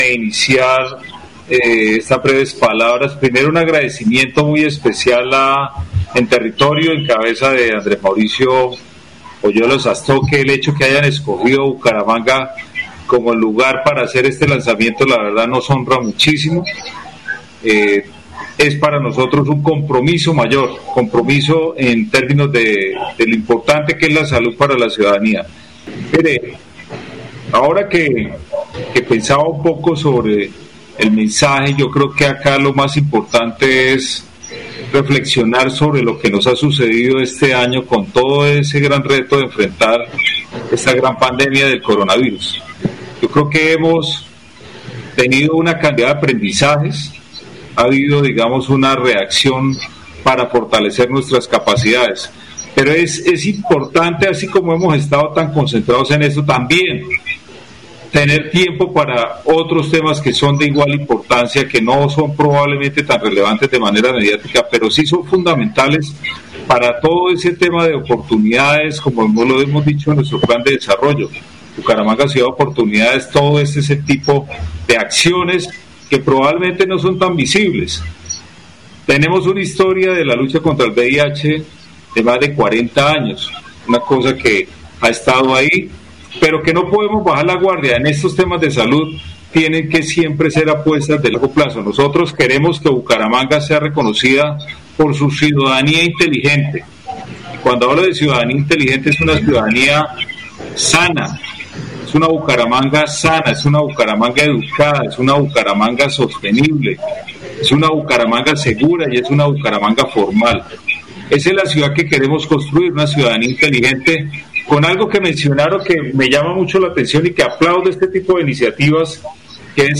Descargar audio: Juan Carlos Cárdenas, alcalde Bucaramanga